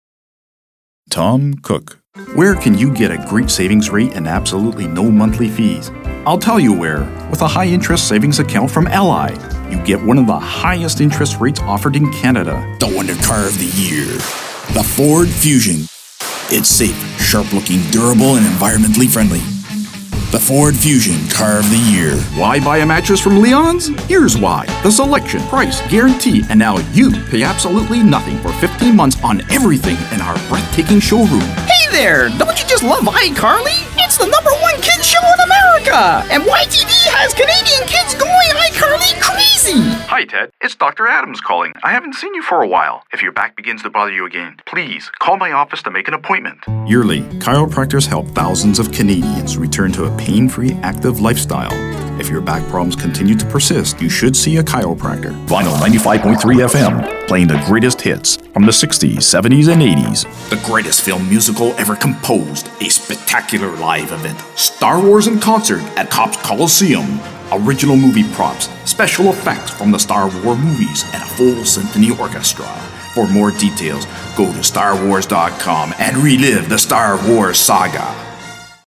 friendly, guy next door, corporate presentations, professional, customer focused
Kein Dialekt
deep, serious, customer focused, radio imaging, voicemail, audio for web sites